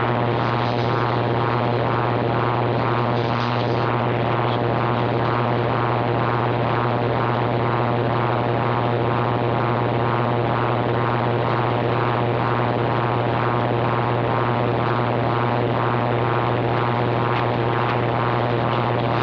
> The noise is intermittent, and comes on for roughly 10-15
The amplitude peaks
Here is what the noise sounded like this morning:
furnace -- most likely caused by either the inducer motor or a DC